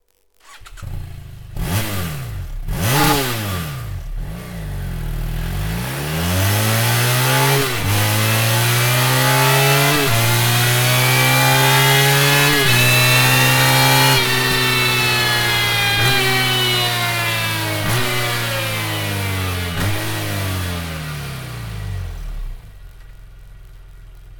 Ääninäytteet
Titaaninen Slip-On-äänenvaimennin BMW F 650 GS (2008-2012)-malliin, joka parantaa suorituskykyä ja tuottaa tunnistettavan Akrapovic-äänen.